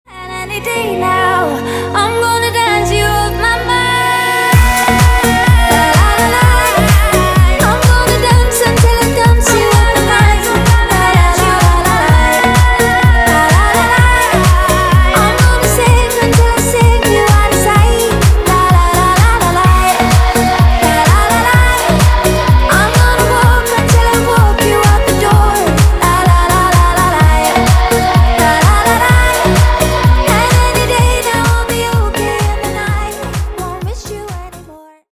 зарубежные клубные